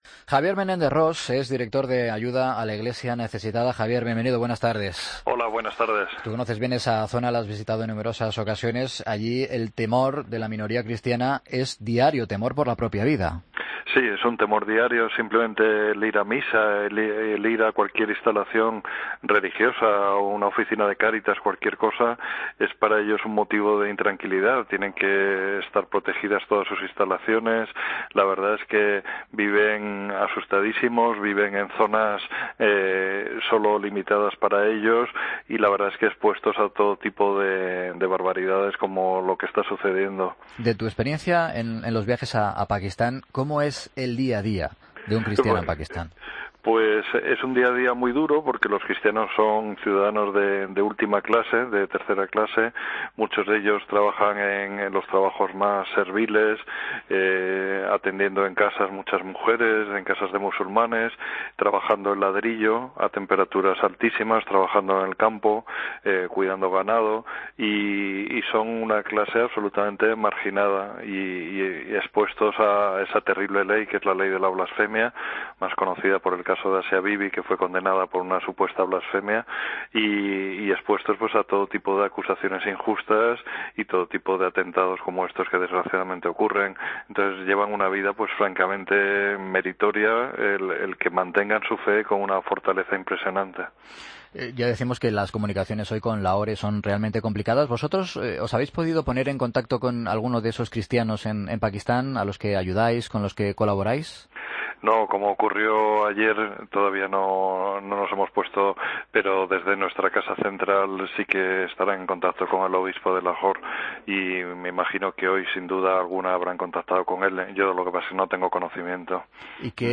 Mediodía COPE Entrevista